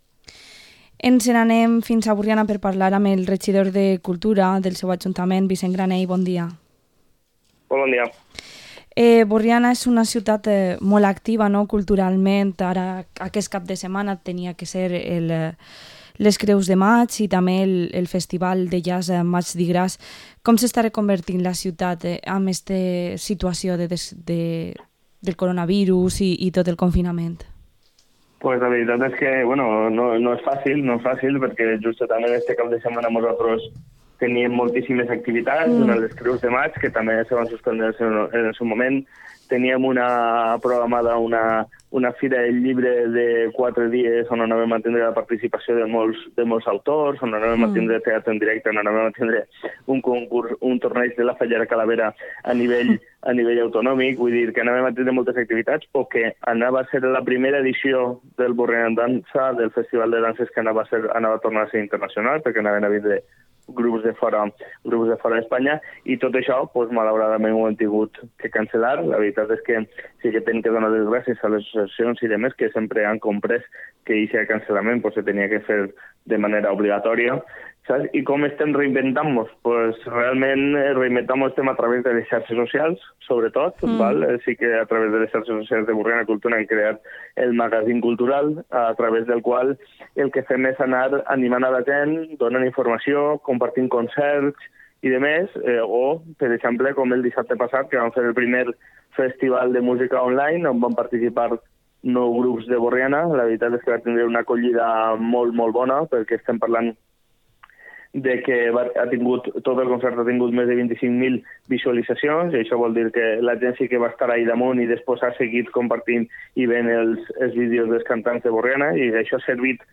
Entrevista al concejal de Cultura de Burriana, Vicent Granell